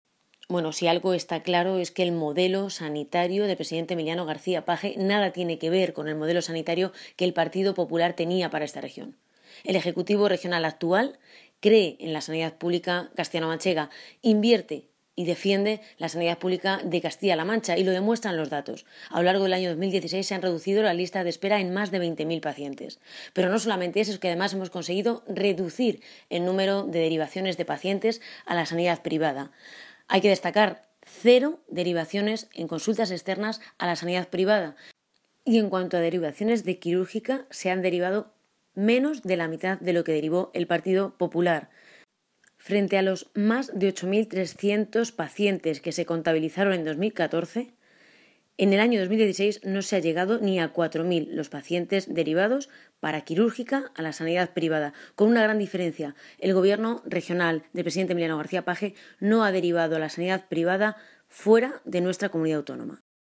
La diputada del Grupo Parlamentario Socialista en las Cortes de Castilla-La Mancha, Ana Isabel Abengózar ha puesto en valor el importante descenso de las derivaciones sanitarias que se están produciendo con el gobierno del presidente García-Page.
Cortes de audio de la rueda de prensa